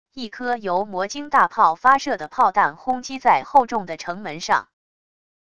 一颗由魔晶大炮发射的炮弹轰击在厚重的城门上wav音频